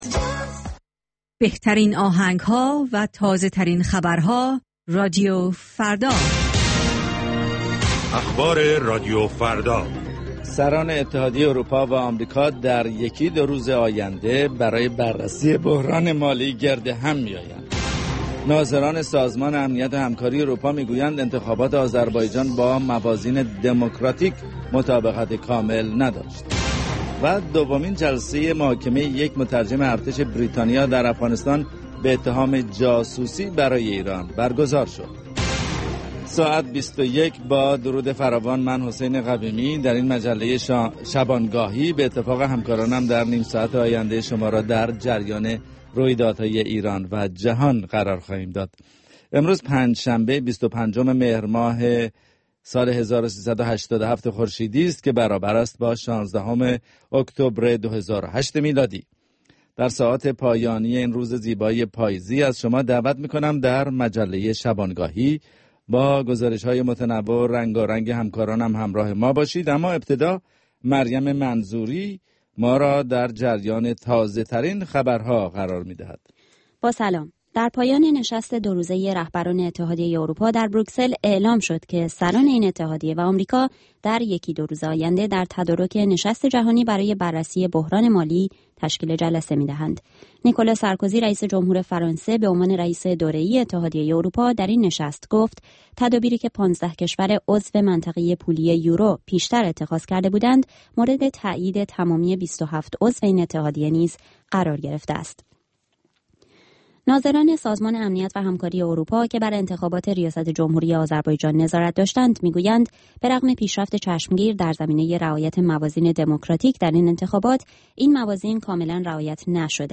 نیم ساعت با تازه ترین خبرها، گزارشهای دست اول در باره آخرین تحولات جهان و ایران از گزارشگران رادیوفردا در چهارگوشه جهان، گفتگوهای اختصاصی با چهره های خبرساز و کارشناسان، و مطالب شنیدنی از دنیای سیاست، اقتصاد، فرهنگ، دانش و ورزش.